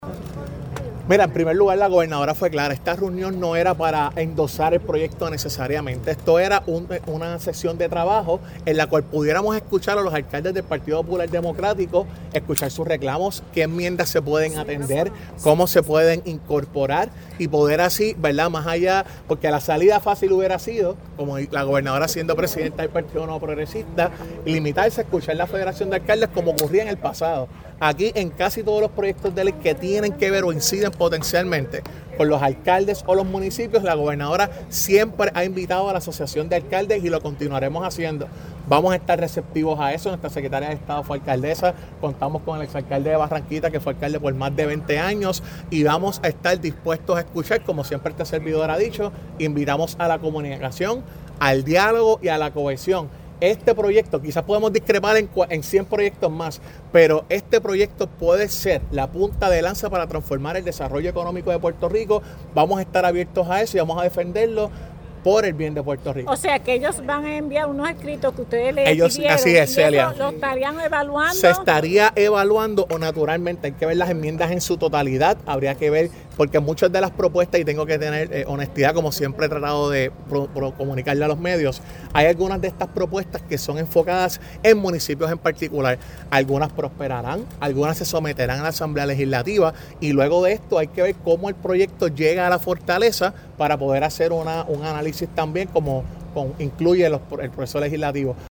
“El proyecto no le quita a los municipios su jerarquía “, dice el titular de Asuntos Públicos tras reunión con la Asociación de Alcaldes (sonido)